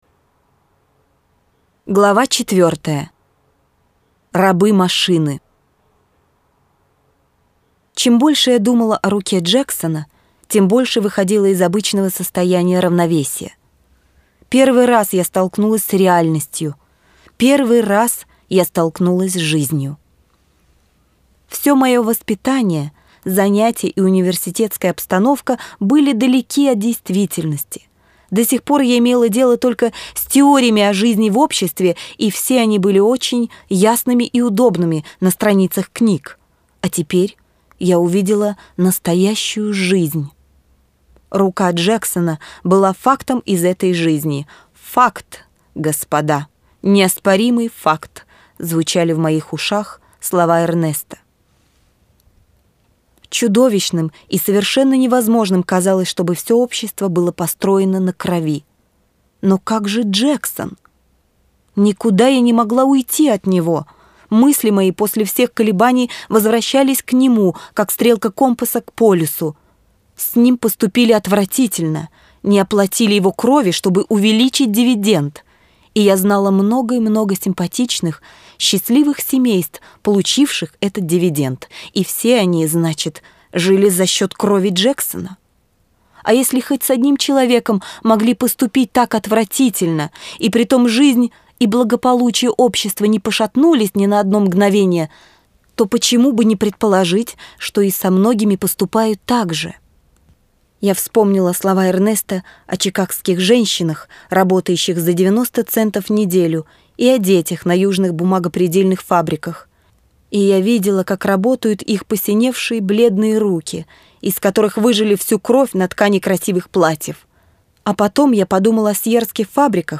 Аудиокнига Железная пята | Библиотека аудиокниг